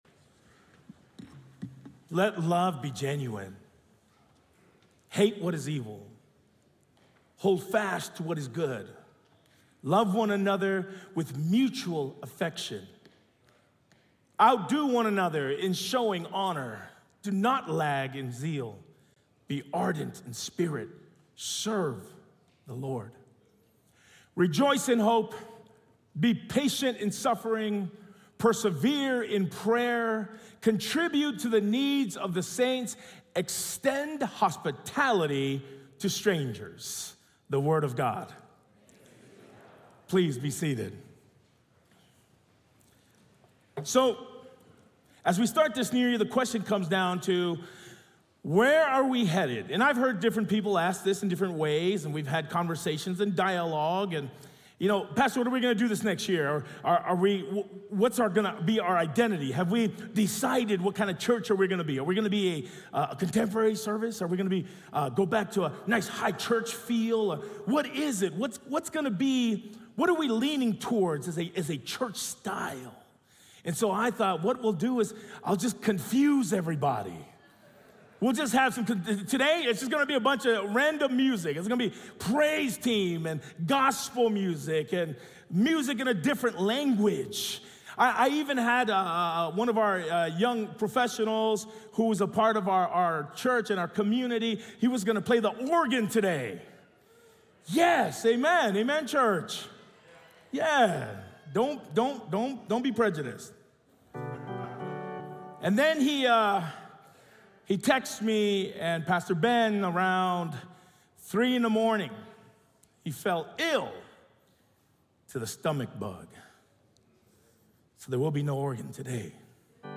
Sermon Series Archive | La Sierra University Church